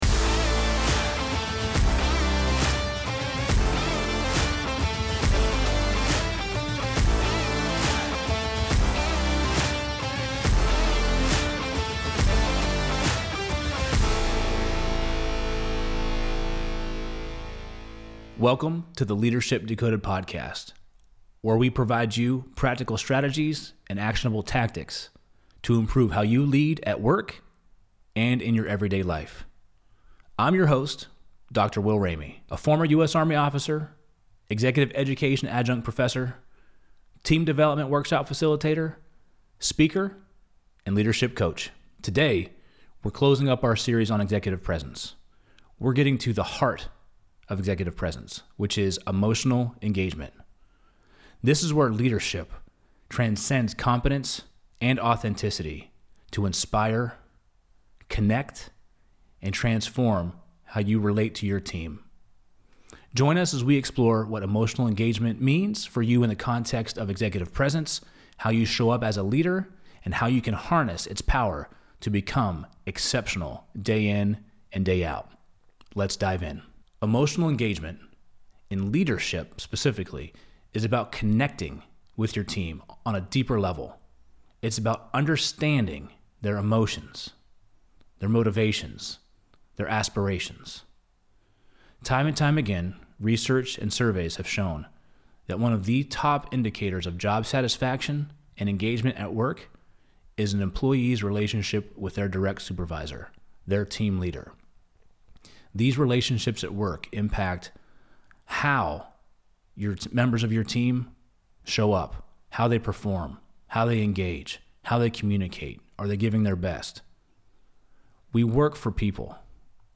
in the Loop Internet studio